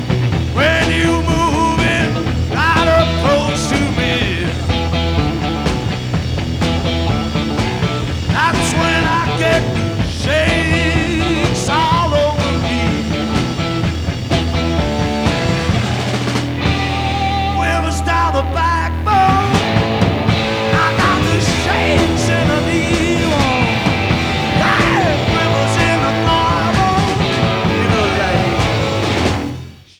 Sound Samples (All Tracks In Stereo)